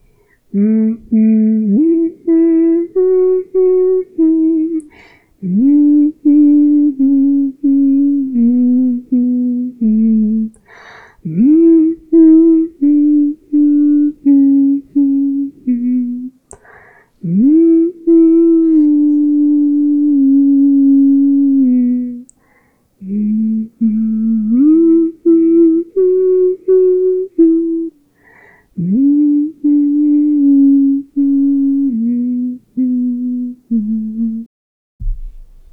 i messed around with two instruments
i kept my mix more simple than anything honestly